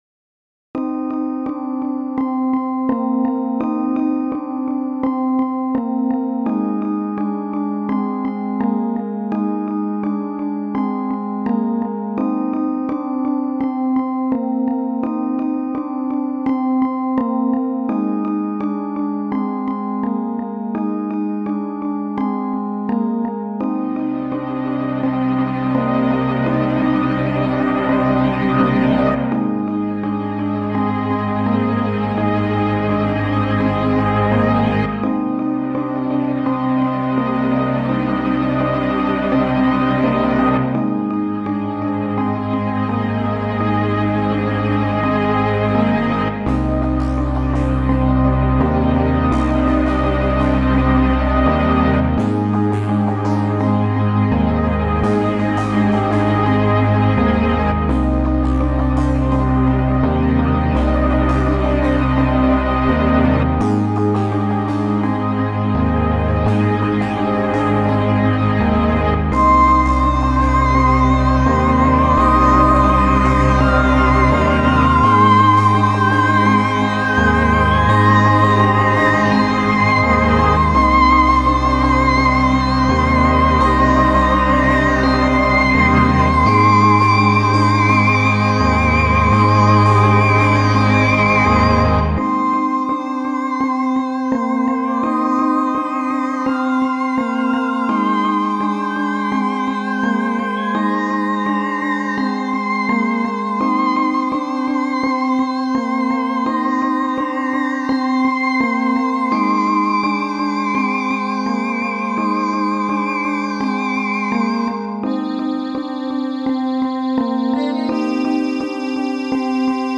immersive and thrilling, nice mixing :P, very adequate to the Theme!